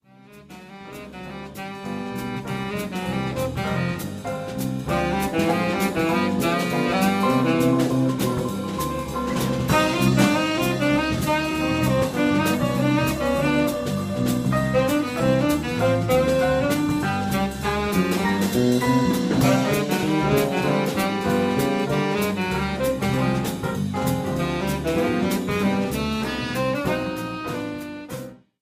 Saxophone, Piano, Bass and Drums Quartet